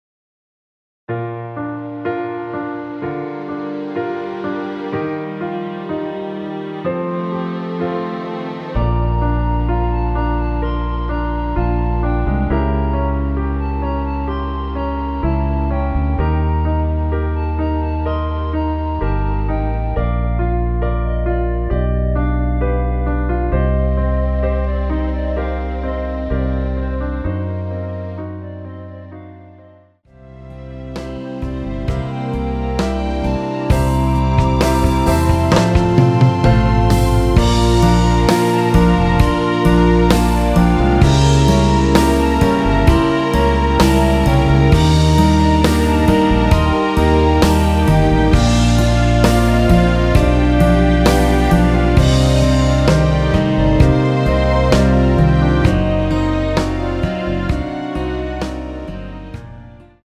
대부분의 여성분이 부르실수 있도록 제작된 키의 MR 입니다.
원키에서(+9)올린 멜로디 포함된 MR입니다.(미리듣기 확인)
Bb
앞부분30초, 뒷부분30초씩 편집해서 올려 드리고 있습니다.